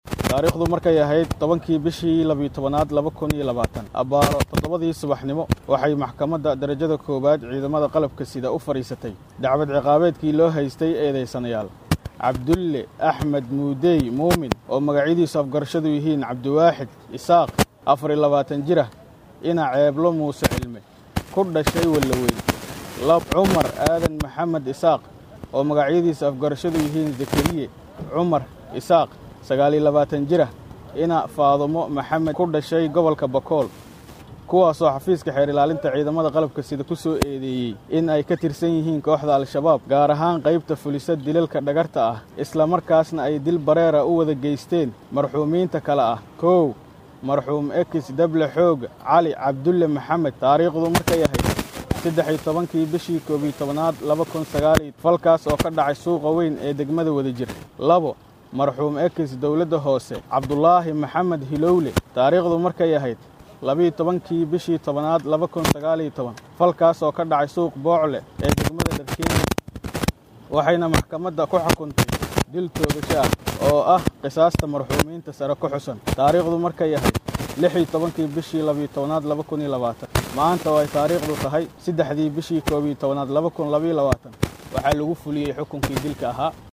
Waxaa dilka ka dib warbaahinta la hadlay ku xigeenka xeer ilaaliyaha maxkamadda ciidamada qalabka sida ee Soomaaliya Maxamad Cabdullaahi Khaliif.